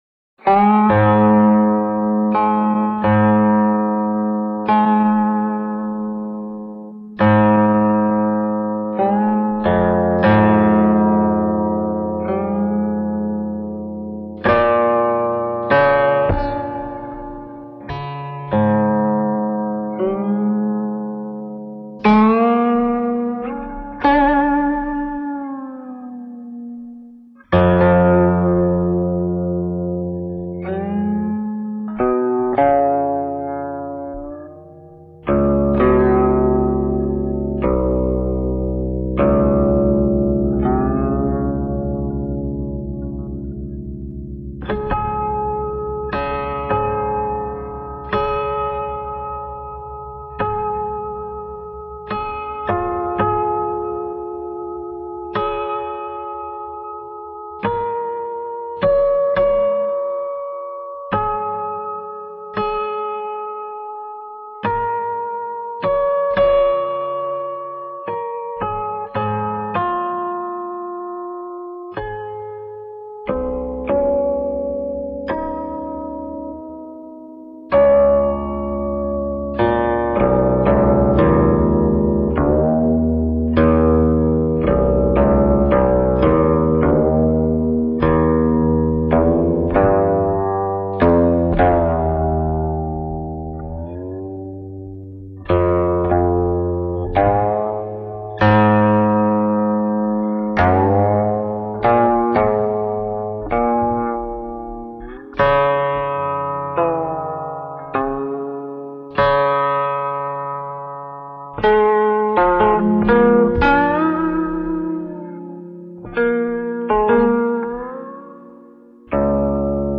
0189-古琴曲古怨.mp3